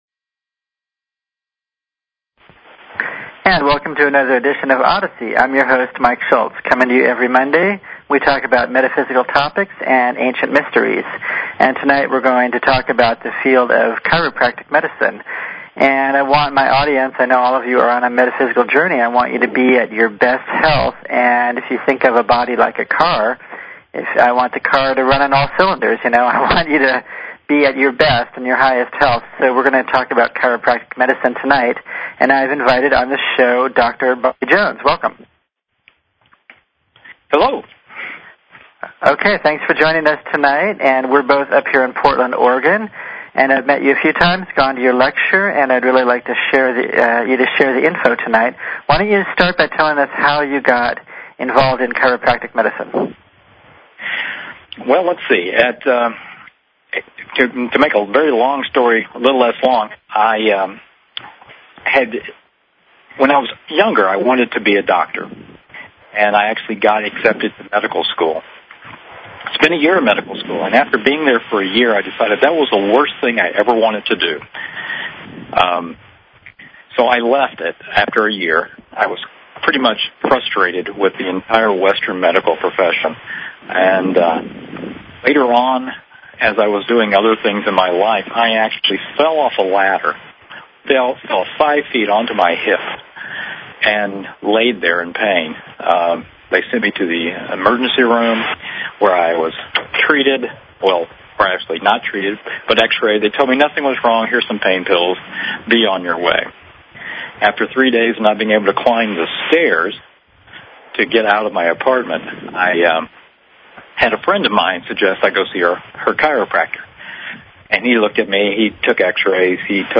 Interview
Talk Show